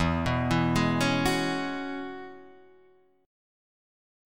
E6add9 chord {x 7 6 6 7 7} chord